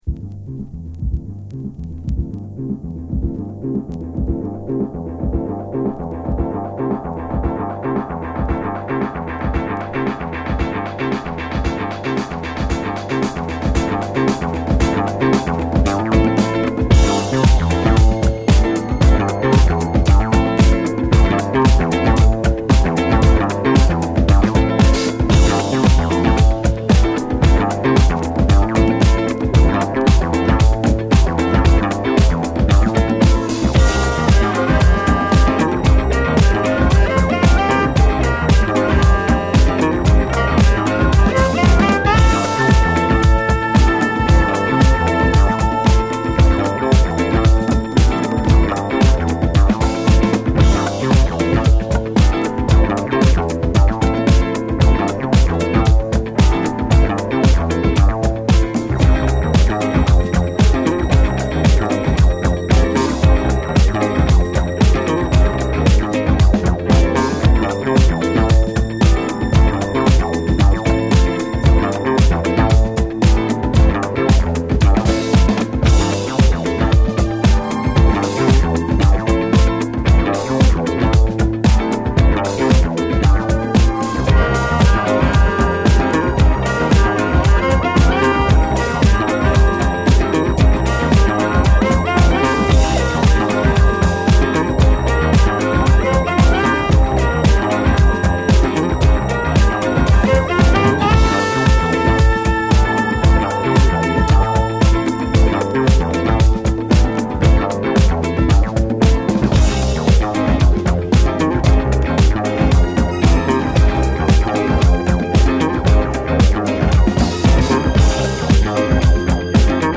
インスト・パートを引き延ばしながらドライブ感のあるブギー・サウンドでEdit！
原曲の持ち味をそのままにビートダウン感覚のミッド・グルーヴでリワーク！